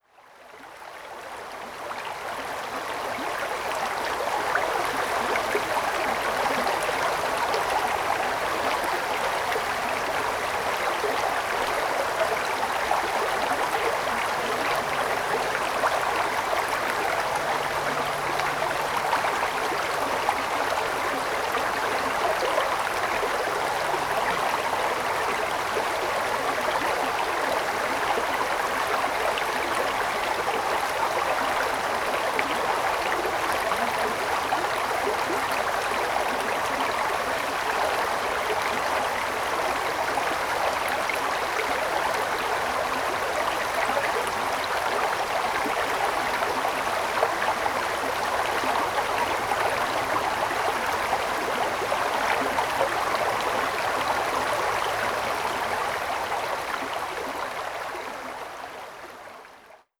Recorded these four soundscapes during a walk on the Copley Ridge and Knarston Creek Loop trail in the hills above Lantzville on December 6, 2021.
4. Knarston Creek at Jessie’s Junction